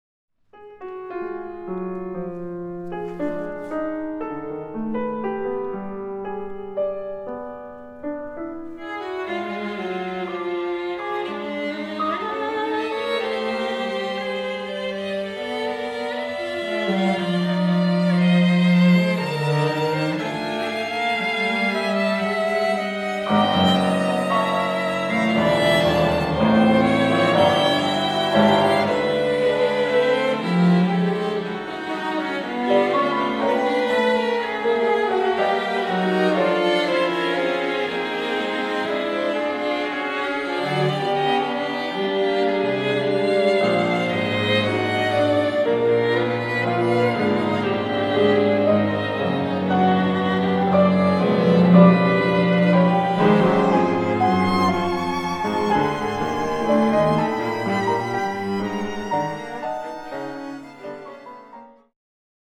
Quintet for piano and strings